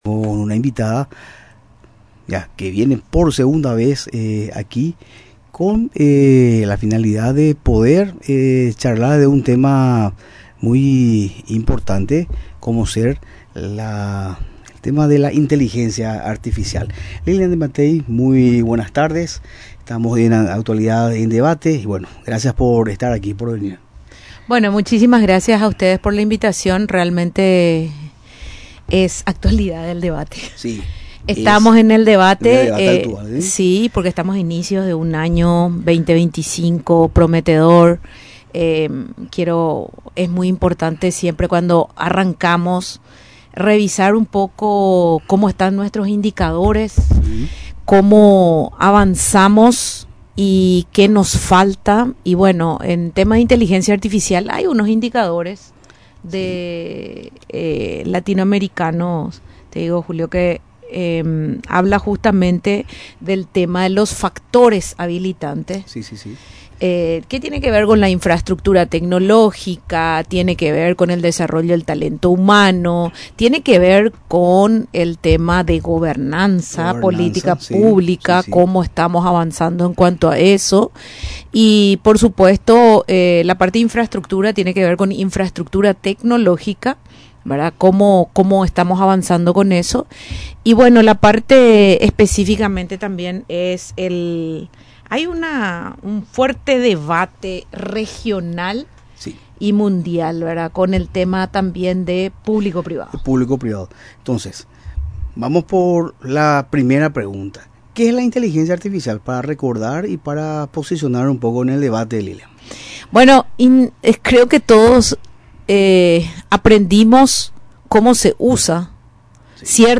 Experta en tecnología visita Radio Nacional para hablar sobre la inteligencia artificial en el Paraguay | RADIO NACIONAL